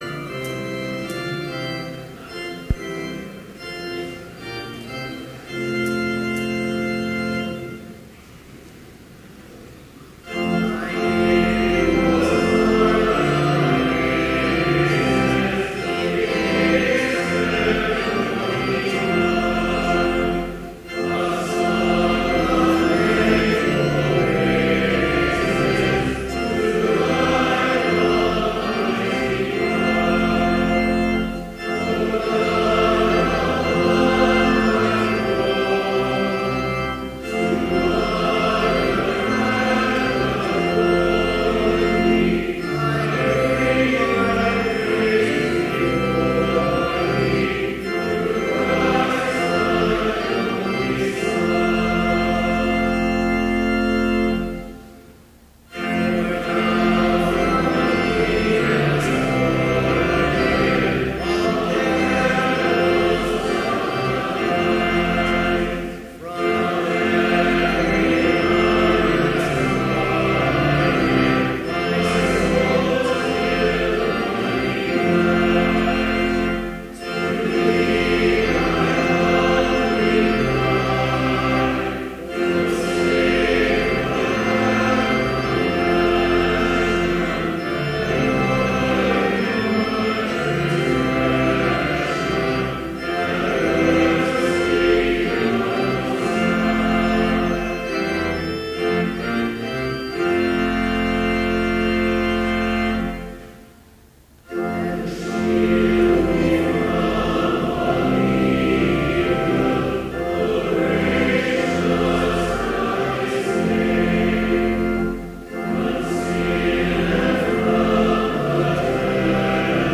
Complete service audio for Chapel - October 10, 2014